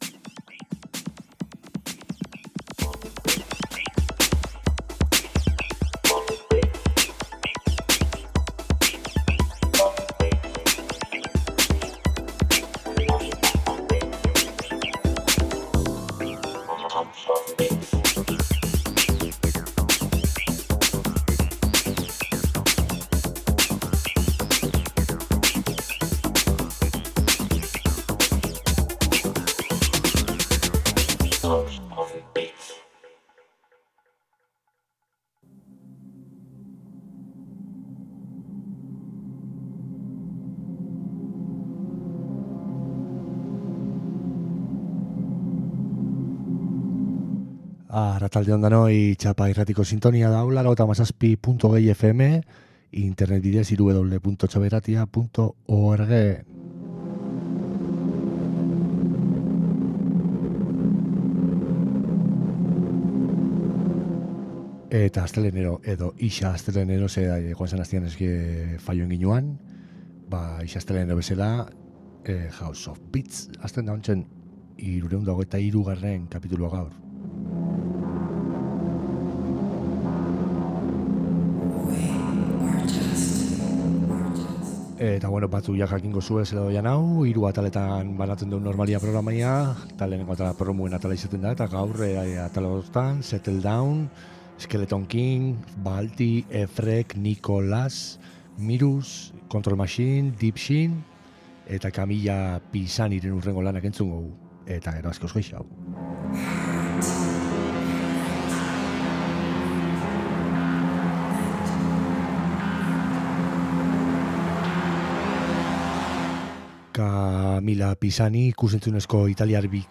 Txapa irratian elektronika kultura sustatzen duen irratsaioa. Elkarrizketak, sesioak, jai alternatiboen berriak eta musikaz gozatzeko asteroko saioa.